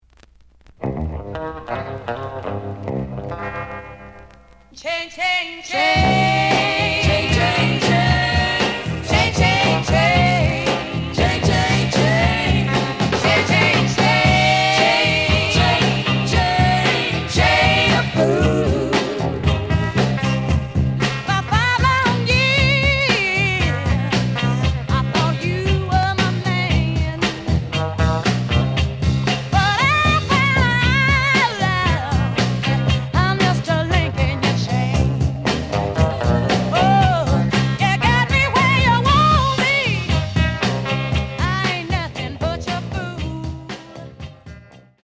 The audio sample is recorded from the actual item.
●Genre: Soul, 60's Soul
Slight edge warp. But doesn't affect playing. Plays good.)